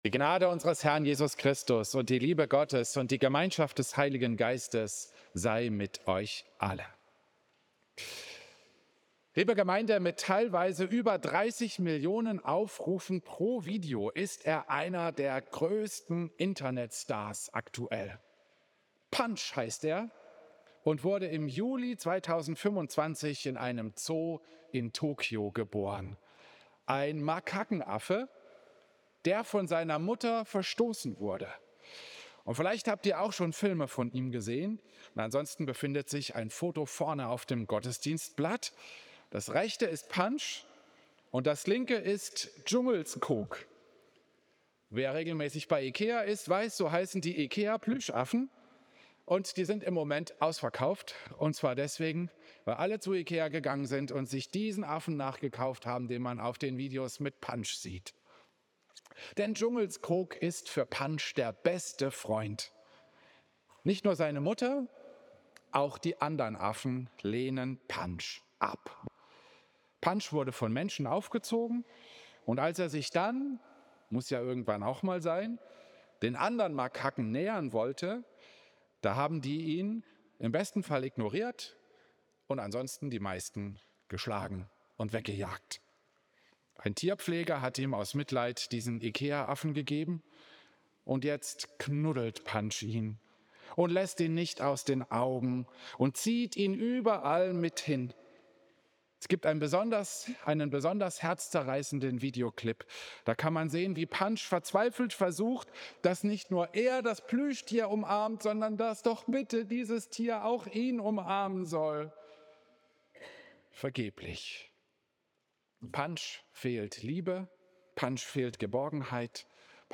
Klosterkirche Volkenroda, 15. März 2026
Predigten